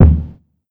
Contact Kick.wav